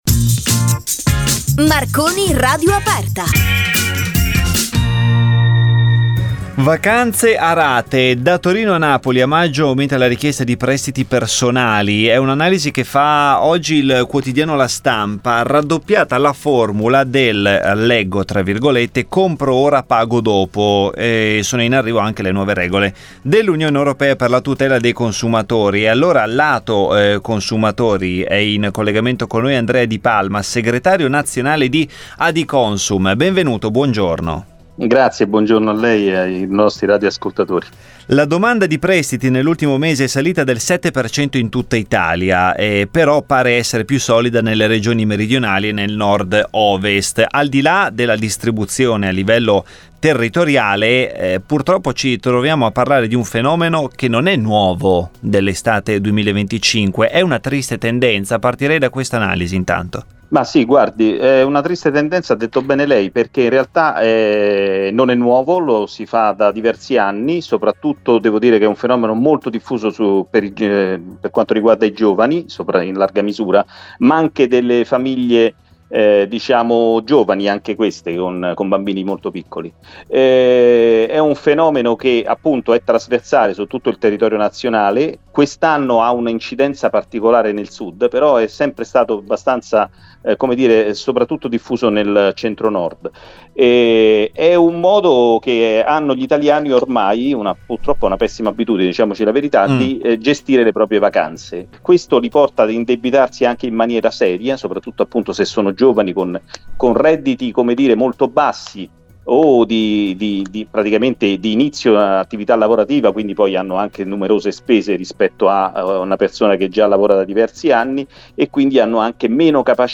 ANALISI